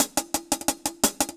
Index of /musicradar/ultimate-hihat-samples/175bpm
UHH_AcoustiHatB_175-01.wav